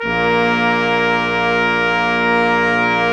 Index of /90_sSampleCDs/Roland LCDP06 Brass Sections/BRS_Quintet/BRS_Quintet % wh